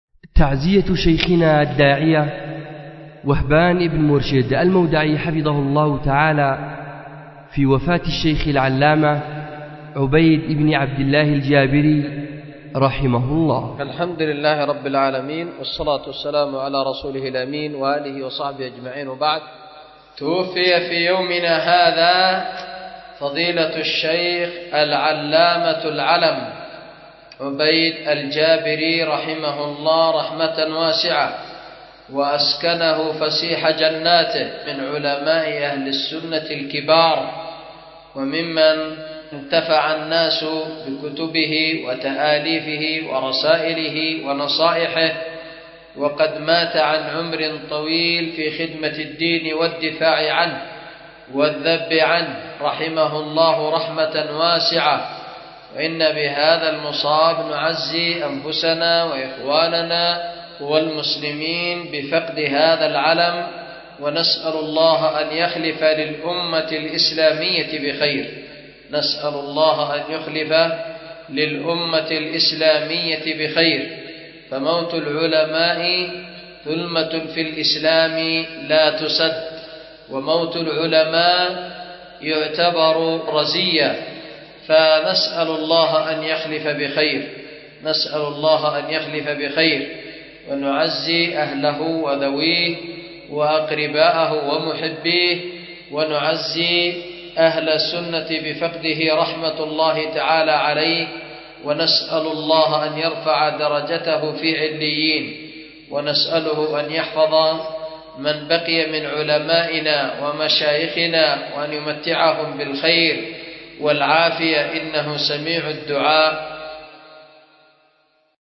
أُلقيت بدار الحديث للعلوم الشرعية بمسجد ذي النورين ـ اليمن ـ ذمار